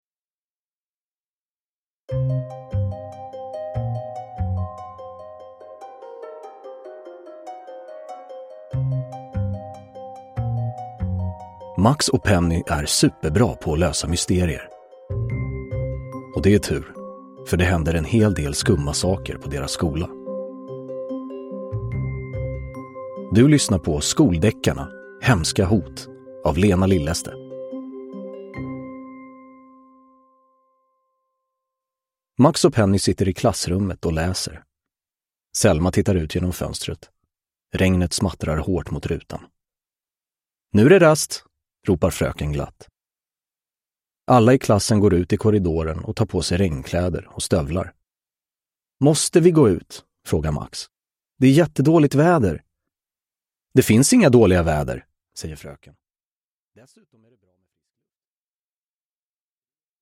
Hemska hot – Ljudbok
Uppläsare: Anastasios Soulis